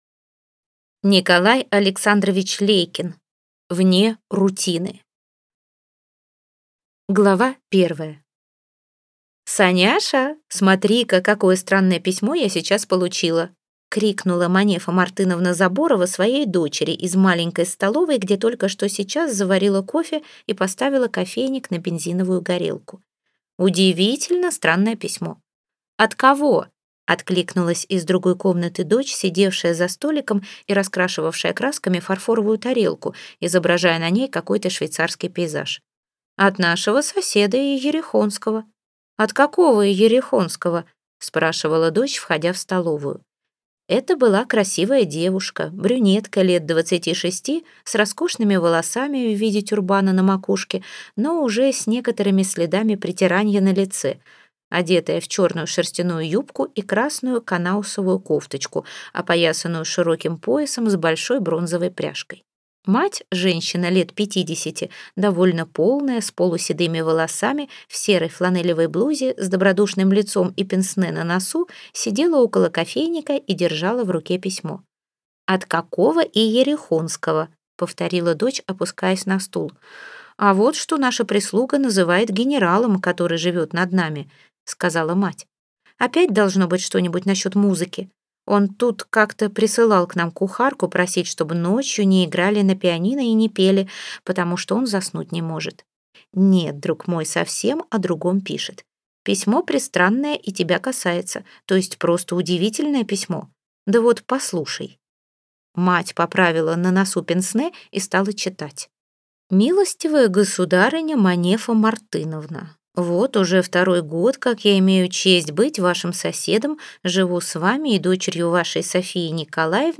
Аудиокнига Вне рутины | Библиотека аудиокниг